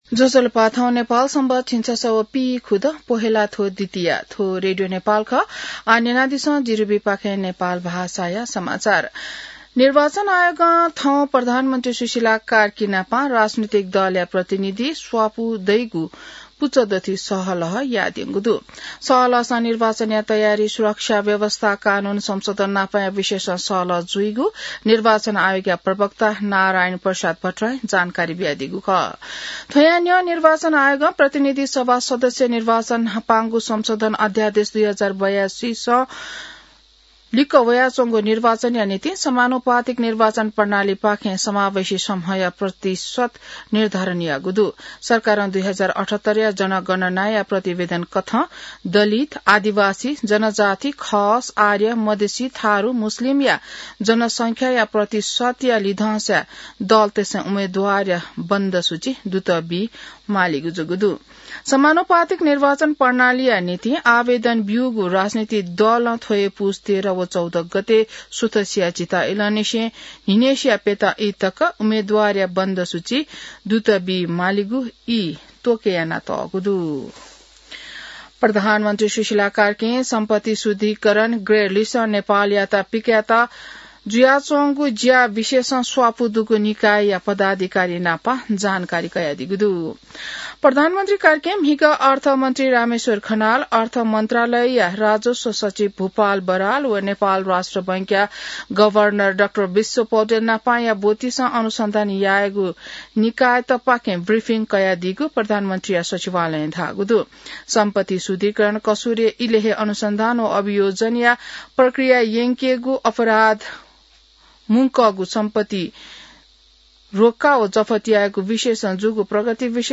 नेपाल भाषामा समाचार : ७ पुष , २०८२